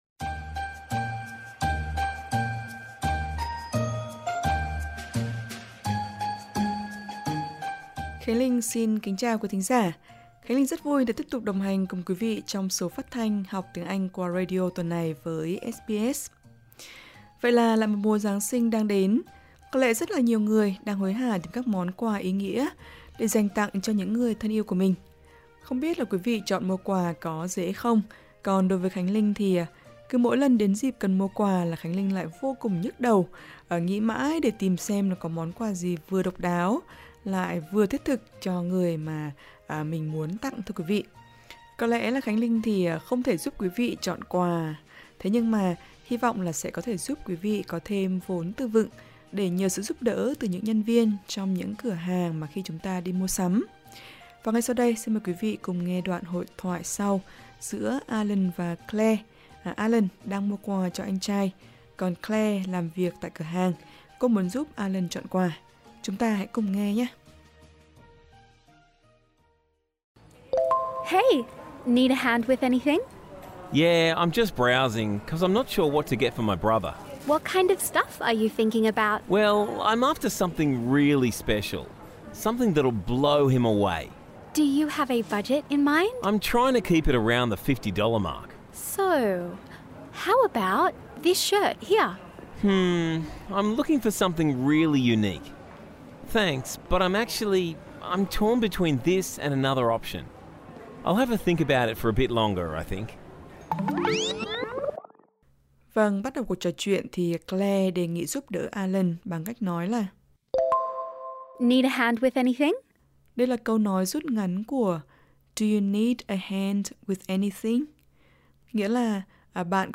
Trong tập Học Tiếng Anh qua radio tuần này, chúng ta sẽ cùng tìm hiểu thêm các từ vựng và cấu trúc câu khi đi mua sắm.